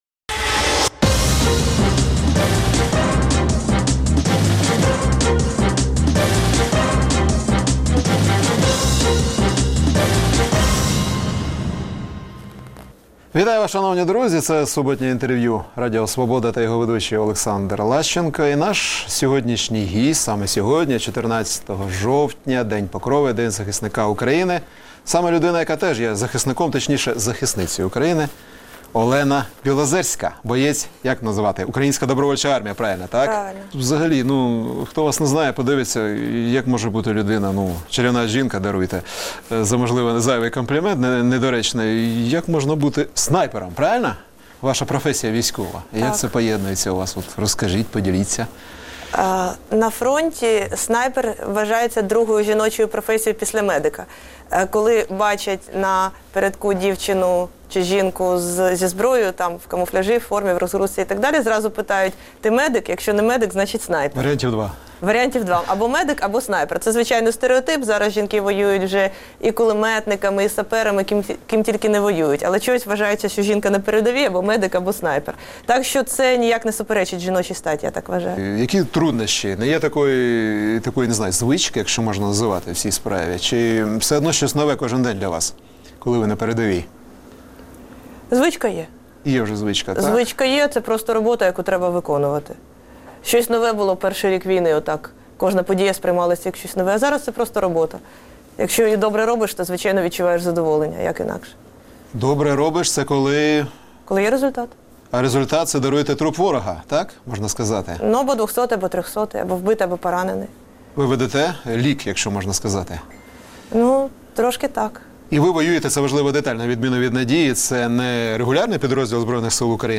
Суботнє інтвер’ю - розмова про актуальні проблеми тижня.